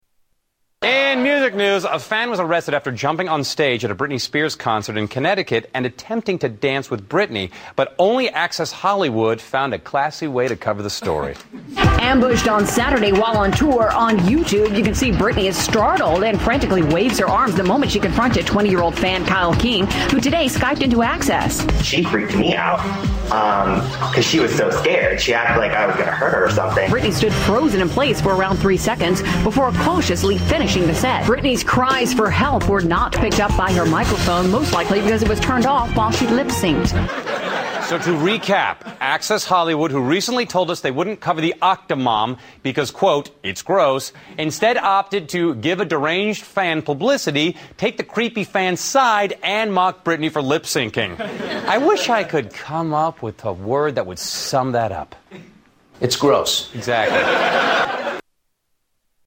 Tags: Comedian Joel McHale Joel McHale Sounds The Soup Community